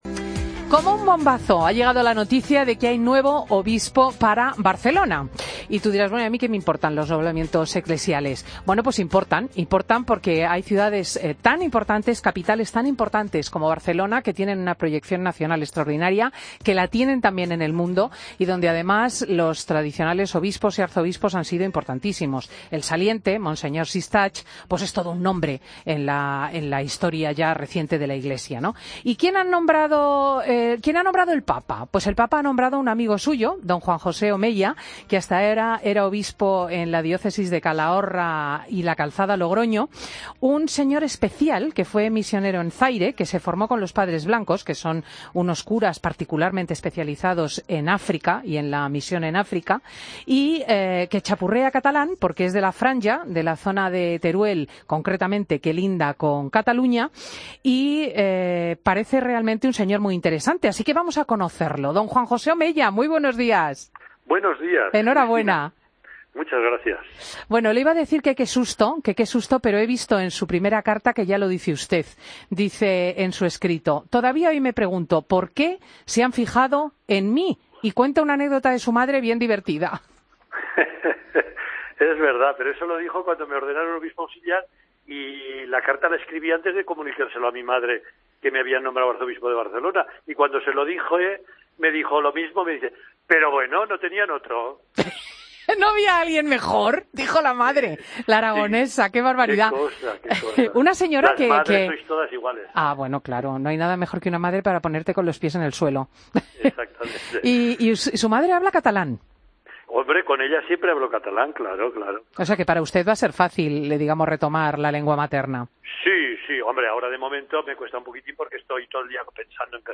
Entrevista a Juan José Omella, nuevo Arzobispo de Barcelona